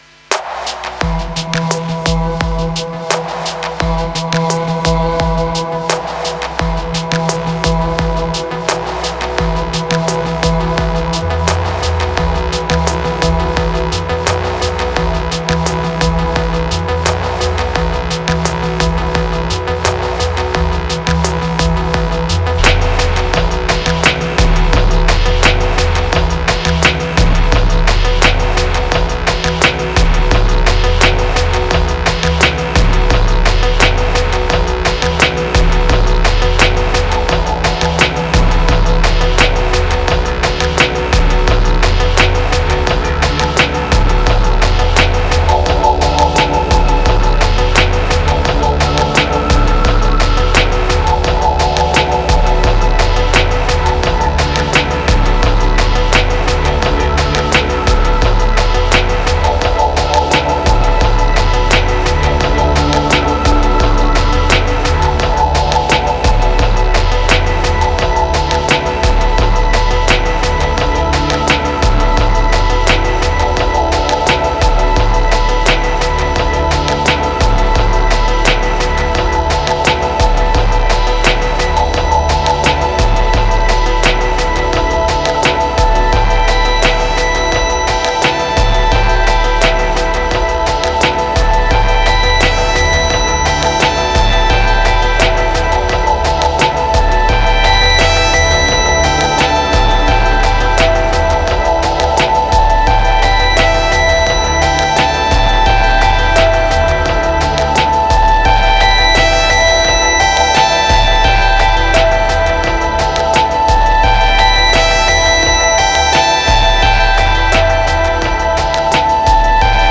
Laidback unity.
70📈 - 93%🤔 - 86BPM🔊 - 2025-12-14📅 - 554🌟
Lazy electro track for a winter end of year afternoon.
Ambient Beats Dark Wave Attic Future Projector Deuce Visions